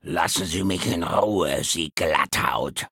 Fallout 3: Audiodialoge
Malegenericghoul_dialoguemsmini_greeting_000c9cd2.ogg